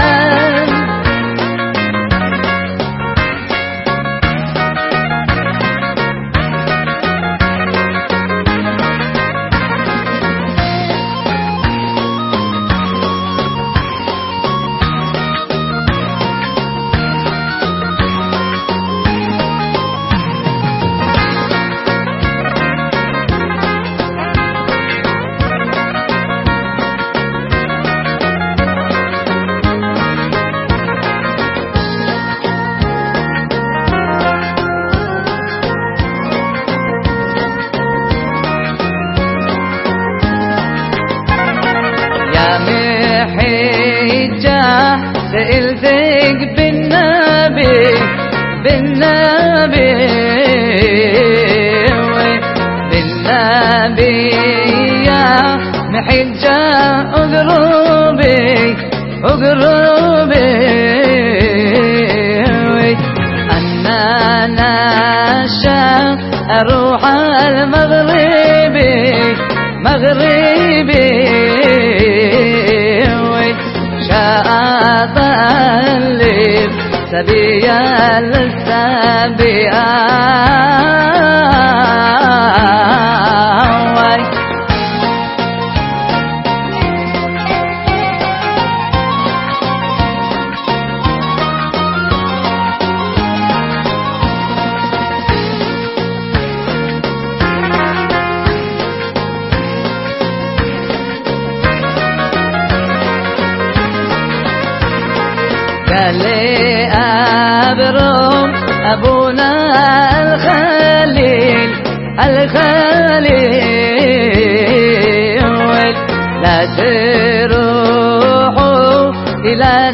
מנהג הנשים לשורר שיר זה בשעת ליווי הכלה לנישואין. ועניין השיר: סיפור נישואי רבקה ויצחק ע"י אליעזר עבד אברהם, ובאים לומר לכלה שזיווגם יעלה יפה כזיווג יצחק ורבקה.